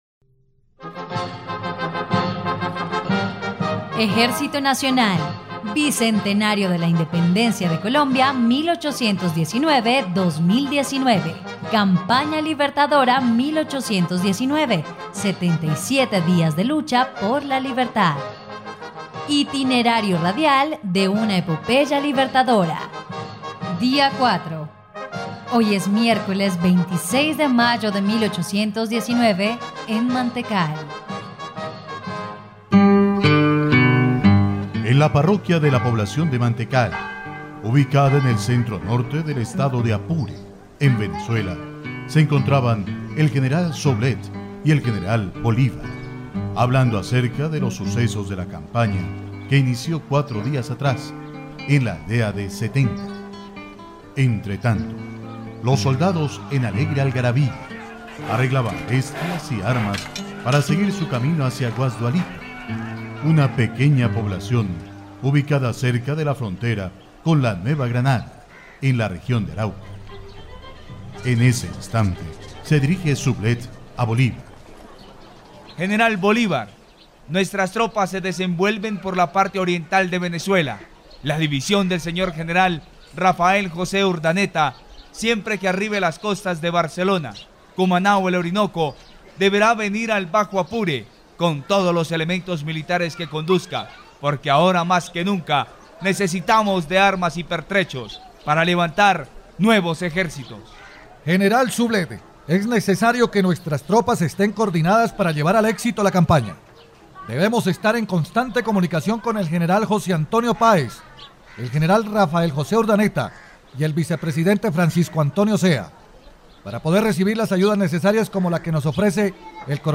dia_04_radionovela_campana_libertadora.mp3